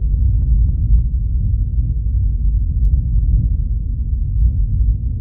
rumble.ogg